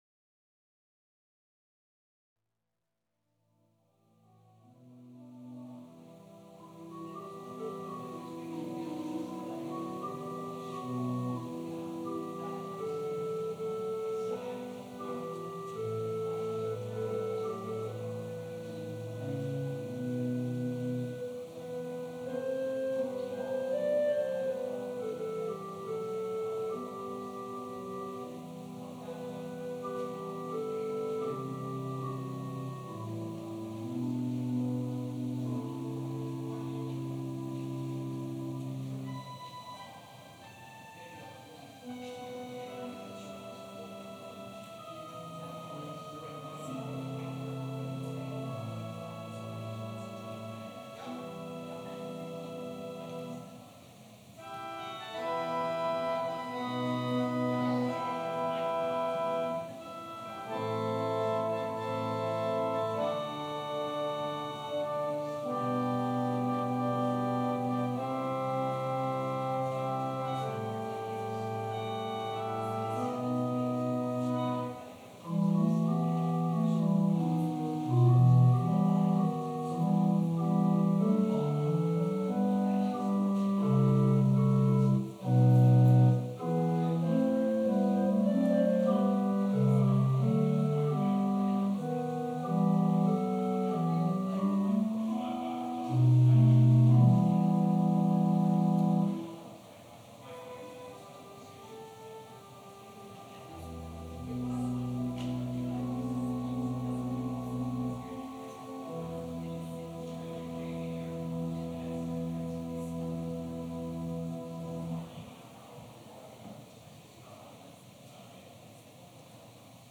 Passage: Luke 4: 1-13 Service Type: Holy Day Service Scriptures and sermon from St. John’s Presbyterian Church on Sunday